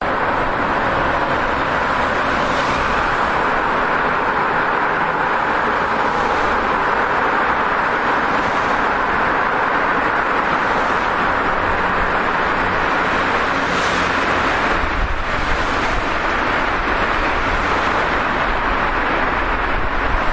高速公路汽车的氛围
描述：救护车沿着一条丰满的道路。
标签： 救护车
声道立体声